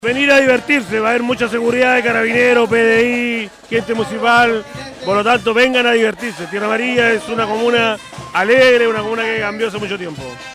Pasado el mediodía de este miércoles, en la comuna de Tierra Amarilla, se realizó la conferencia de prensa donde se hizo el lanzamiento del Carnaval del Pullay 2020.